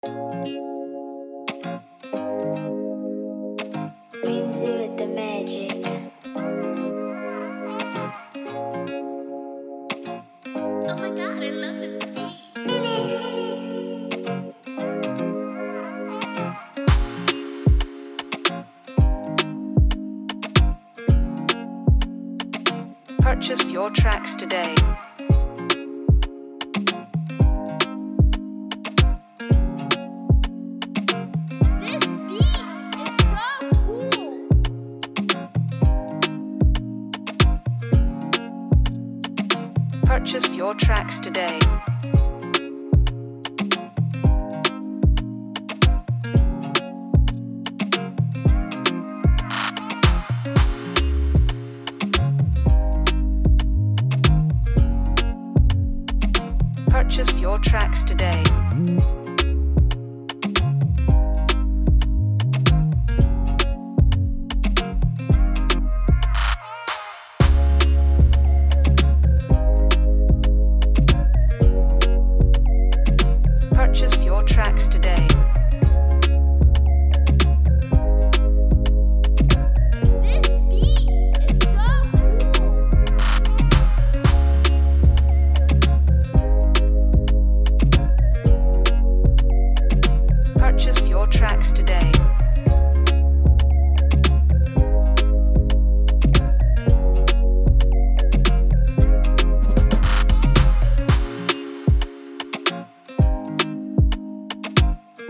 a scintillating instrumental